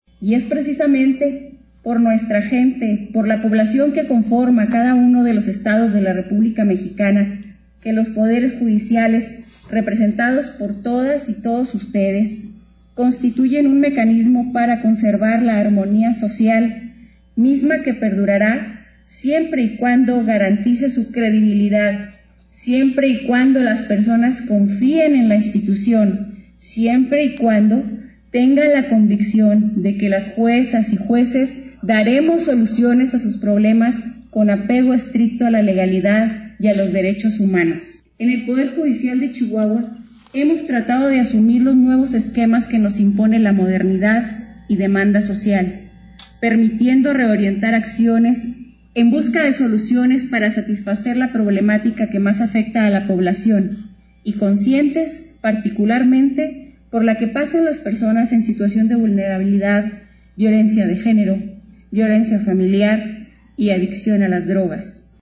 Inaugura Gobernadora la Tercera Asamblea Plenaria Ordinaria de la Comisión Nacional de Tribunales de Justicia (CONATRIB)
AUDIO: MYRIAM HERNÁNDEZ ACOSTA, PRESIDENTA DEL TRIBUNAL SIUPERIOR DE JUSTICIA (TSJ)